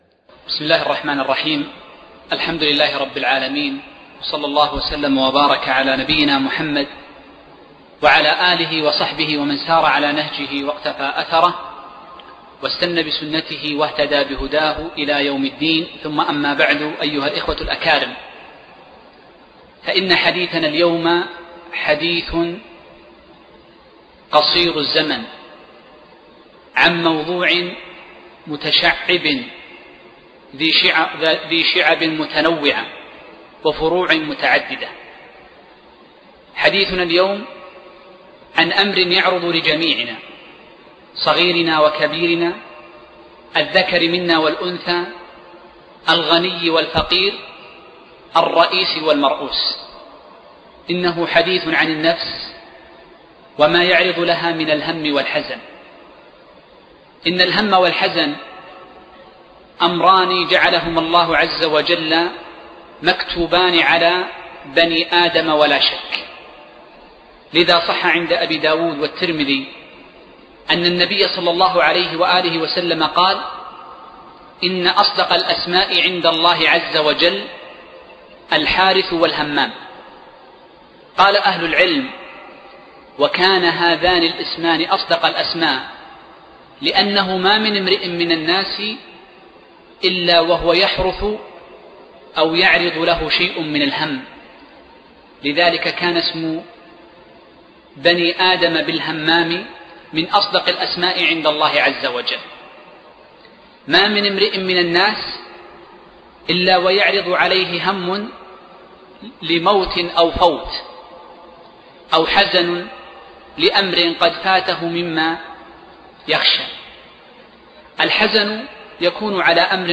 محاضرة قيمة - وداعا للهموم والأحزان